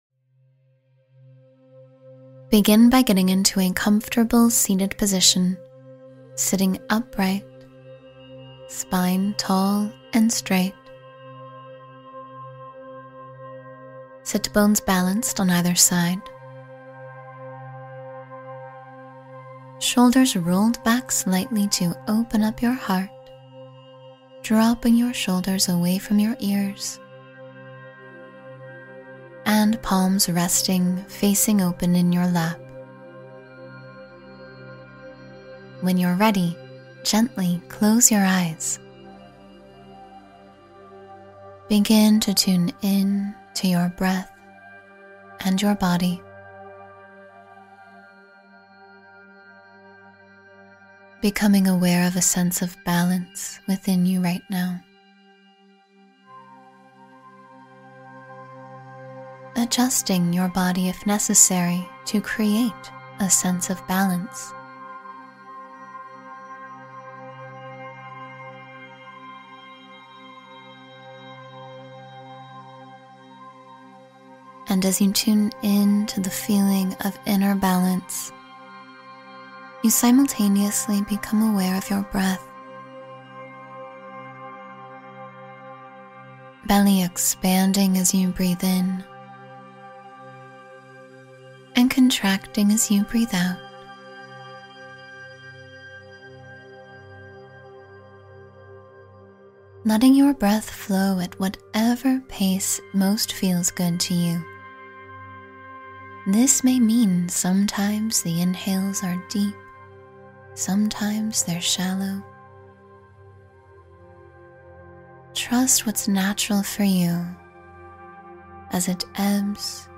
Calm Your Overactive Mind in 10 Minutes — Meditation for Relaxation and Focus